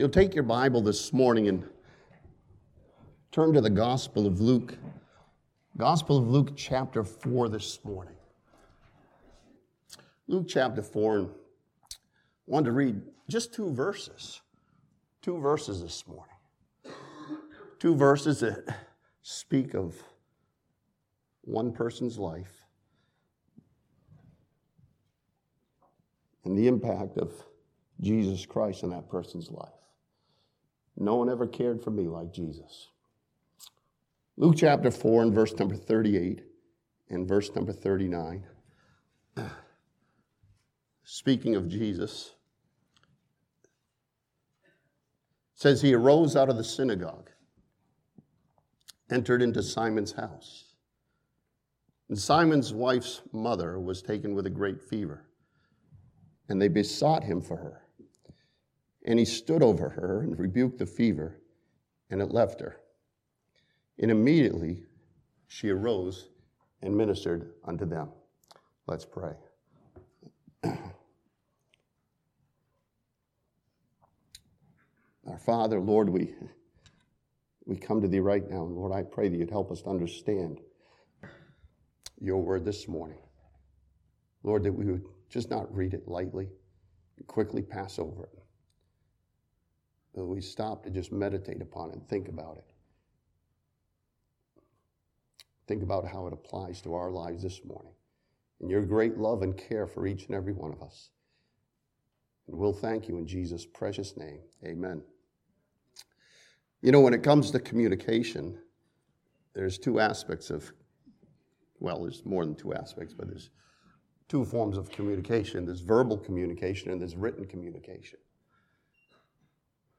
This sermon from Luke chapter 4 follows Jesus as in His ministry of healing and learns that He cares for you and me.